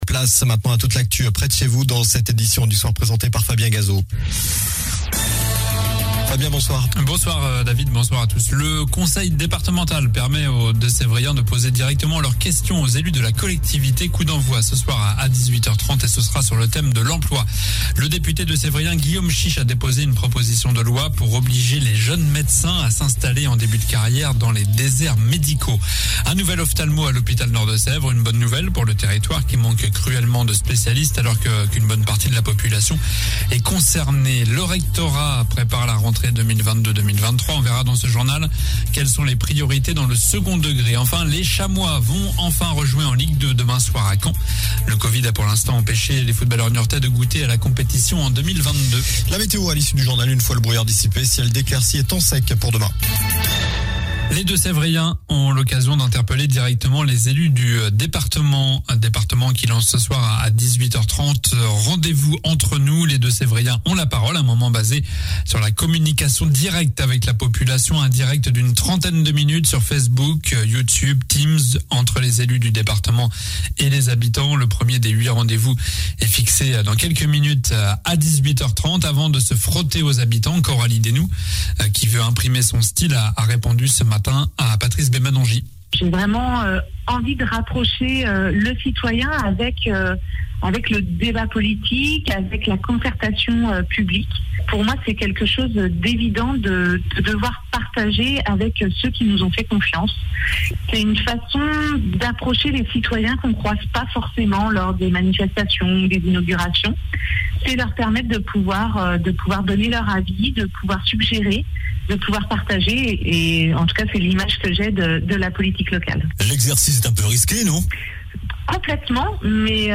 Journal du jeudi 27 janvier (soir)